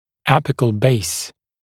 [‘æpɪkl beɪs][‘эпикл бэйс]апикальный базис